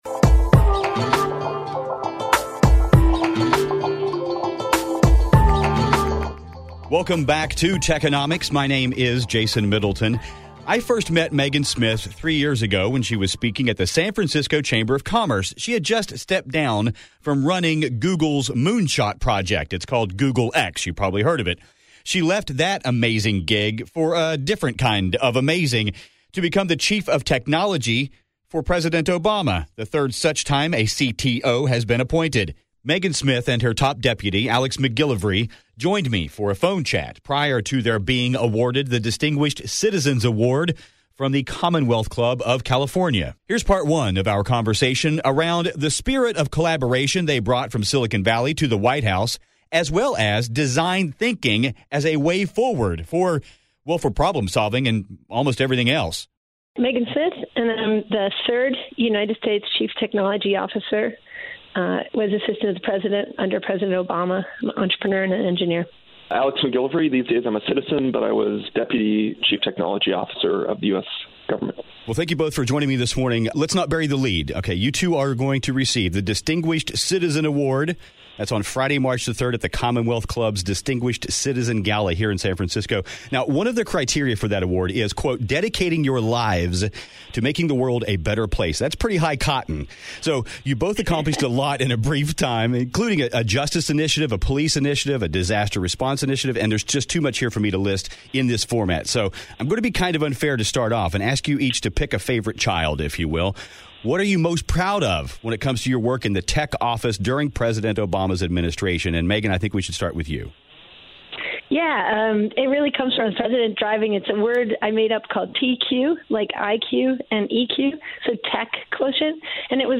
The position of Chief Technology Officer is relatively new in the White House and we get to talk to the 3rd CTO, ever, who worked in the Obama Administration.